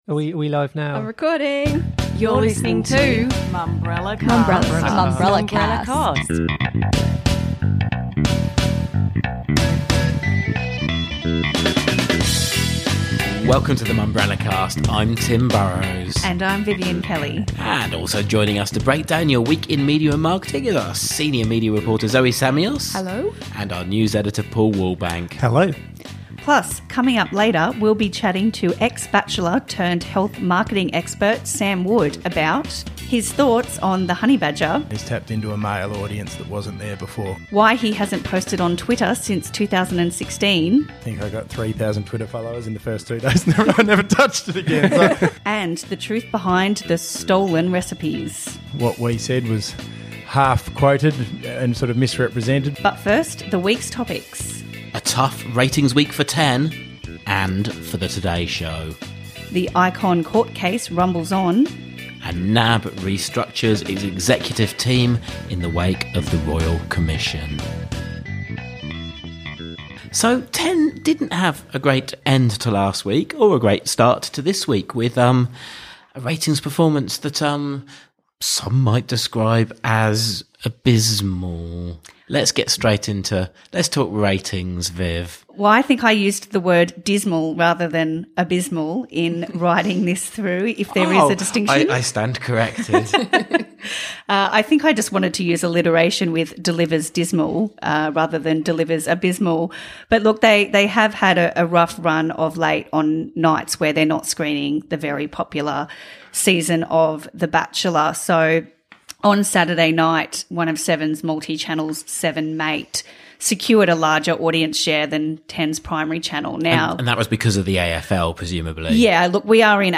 As well as the most talked about stories of the week, former reality TV star and Bachelor Sam Wood joins some of the editorial team to discuss his thriving business, debunk claims 28 by Sam Wood stole recipes, and unpack the reasons why he hasn’t posted on Twitter since 2016.